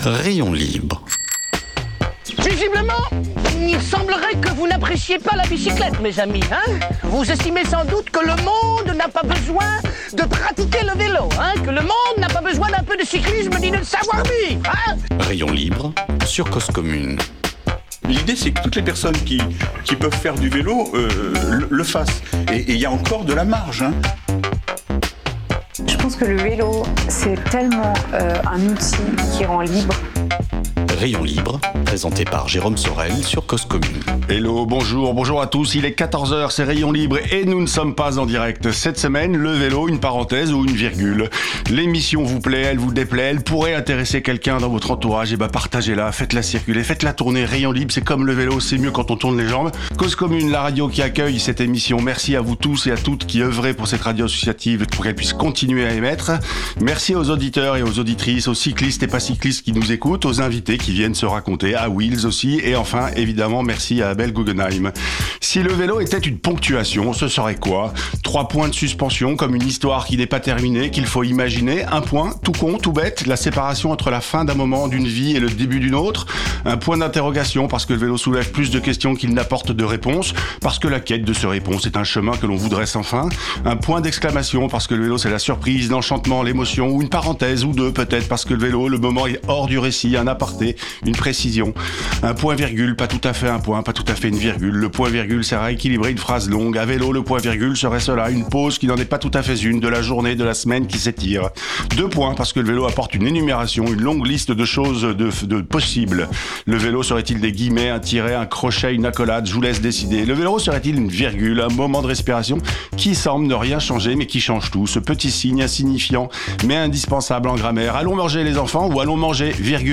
Pas d’agenda émission enregistrée